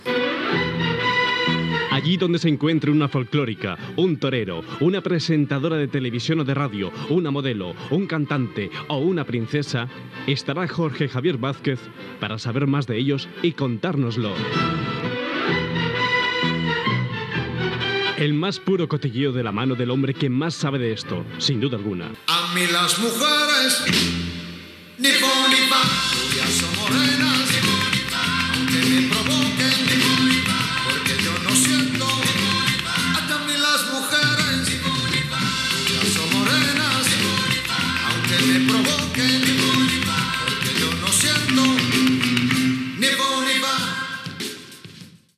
Careta de la secció dedicada a la premsa del cor, presentada per Jorge Javier Vázquez
Entreteniment